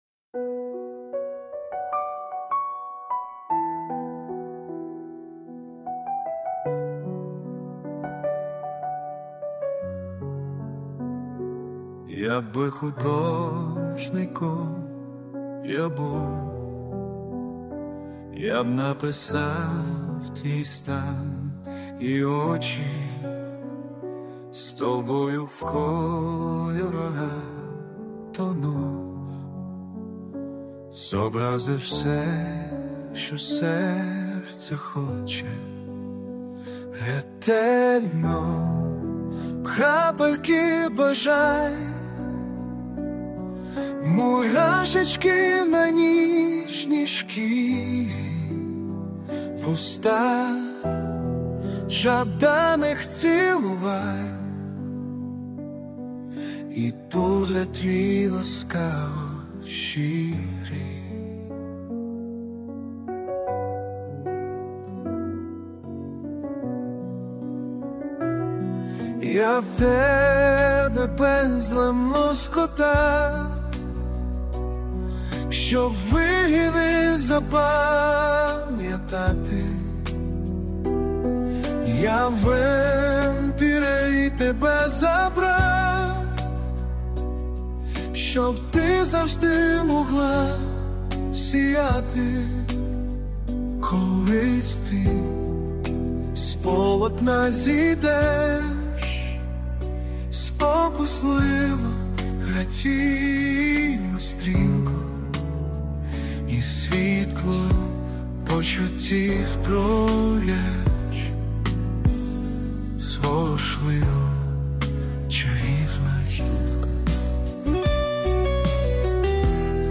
Текст - автора, виконання пісні - штучний інтелект
ТИП: Пісня
СТИЛЬОВІ ЖАНРИ: Ліричний
ВИД ТВОРУ: Авторська пісня